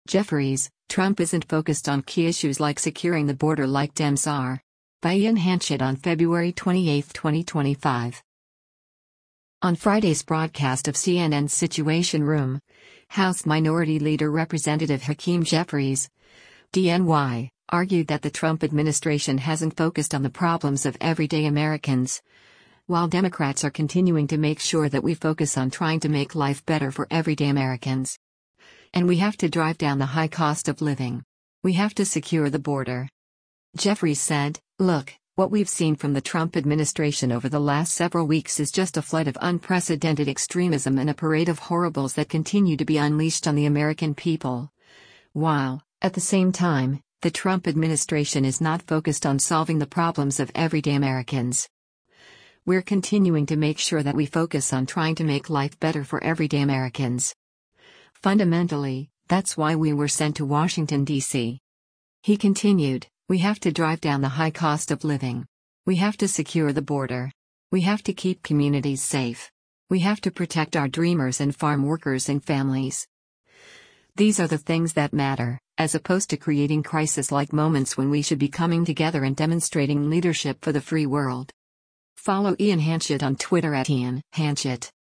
On Friday’s broadcast of CNN’s “Situation Room,” House Minority Leader Rep. Hakeem Jeffries (D-NY) argued that the Trump administration hasn’t focused on the problems of everyday Americans, while Democrats are “continuing to make sure that we focus on trying to make life better for everyday Americans.” And “We have to drive down the high cost of living. We have to secure the border.”